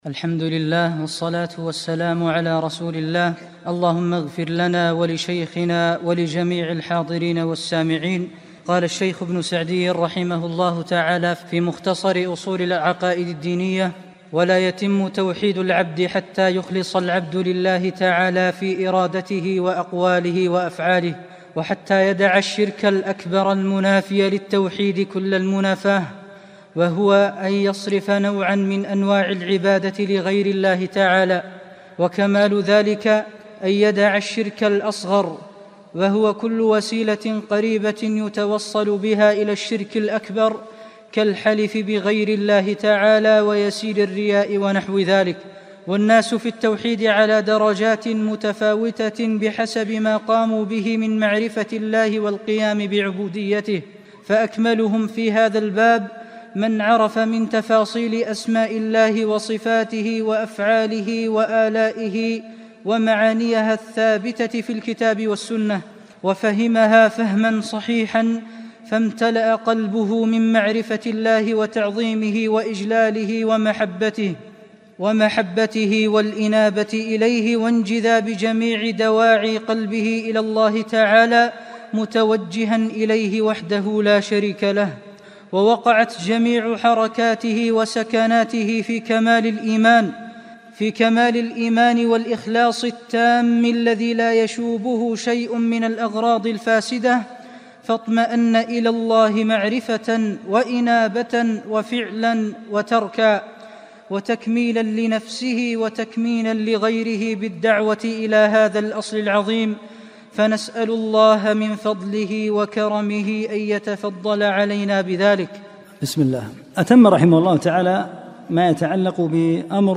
محاضرتان صوتيتان